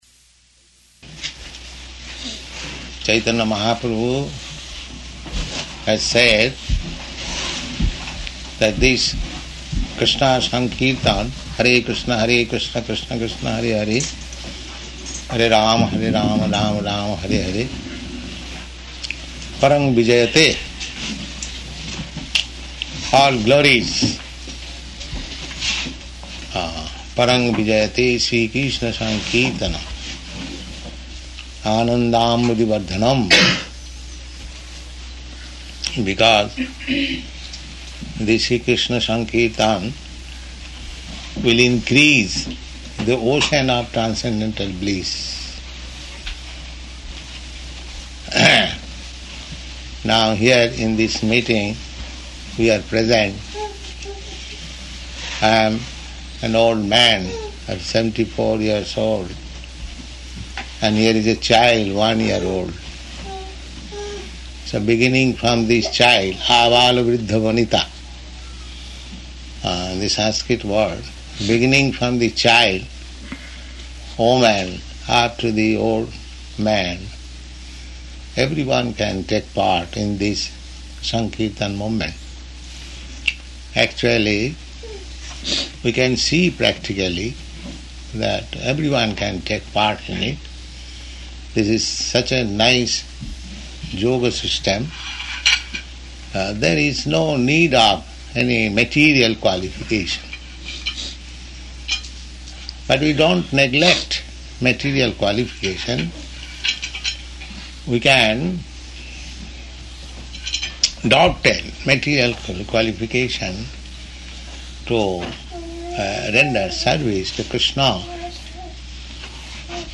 Initiation Lecture
Type: Lectures and Addresses
Location: New Vrindavan
[child making noises like japa ] He is trying to...